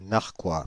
Ääntäminen
Synonyymit acerbe Ääntäminen Paris Tuntematon aksentti: IPA: /naʁ.kwa/ Haettu sana löytyi näillä lähdekielillä: ranska Käännöksiä ei löytynyt valitulle kohdekielelle.